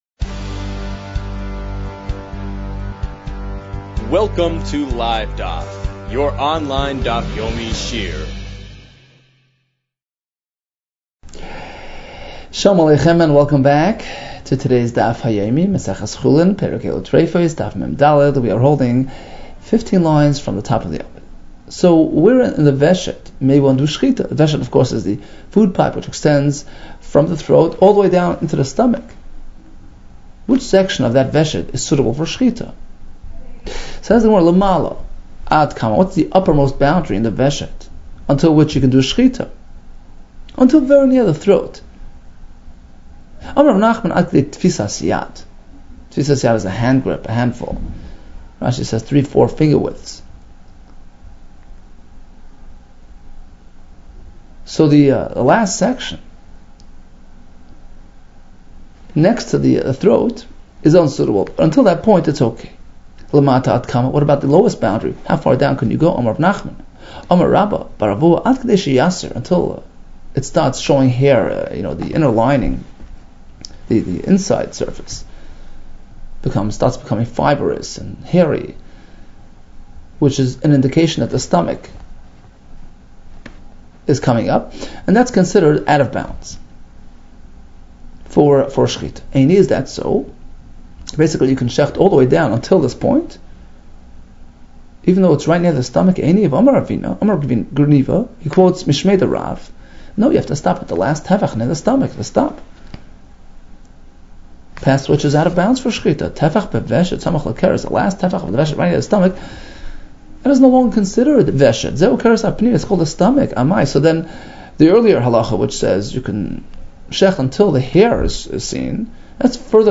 Chulin 43 - חולין מג | Daf Yomi Online Shiur | Livedaf